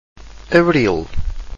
Category: Pronunciation
Abreel